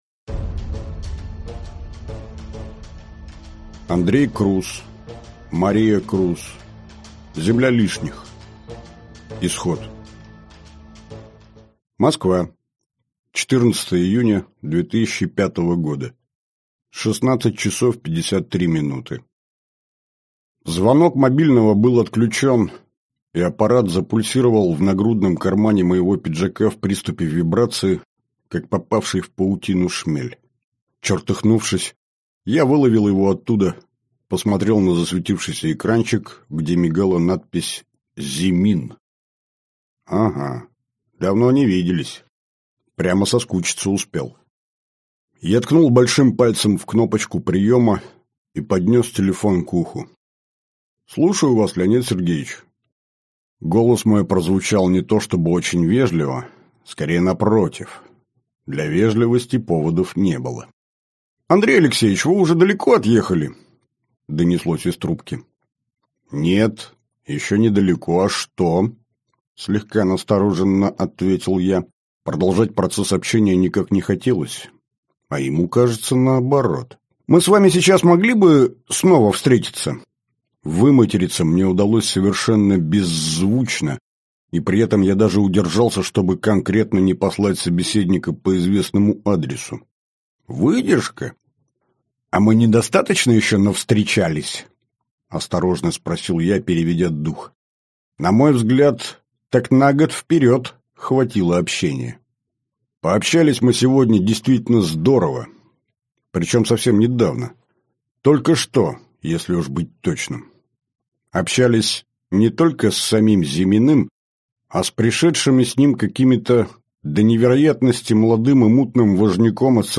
Аудиокнига Земля лишних. Исход | Библиотека аудиокниг